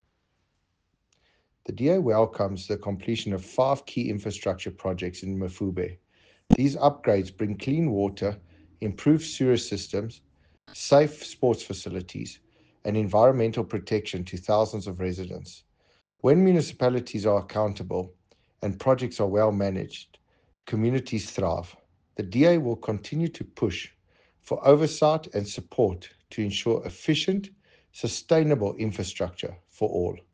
English soundbite by Cllr Nick Muller,